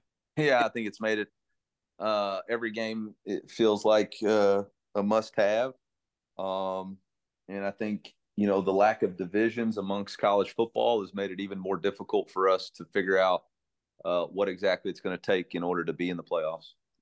Missouri head coach Eliah Drinkwitz spoke on the importance of the matchup.
DrinkwitzOnPlayoffs.wav